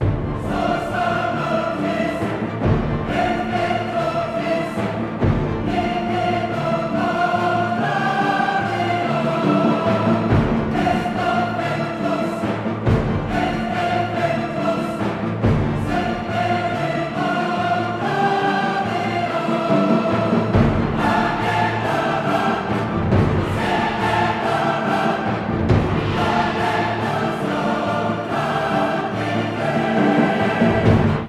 L’œuvre s’ouvre et se conclut avec le célèbre O Fortuna, un chœur ensorcelant, puissant, tout simplement inoubliable. Une distribution canadienne de premier plan magnifie les thèmes du destin, de l’amour et de la fortune mis en relief par Orff par une utilisation saisissante des chœurs, de l’orchestre et des percussions : un concert qu’on ne sera pas près d’oublier!